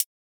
HH 2.wav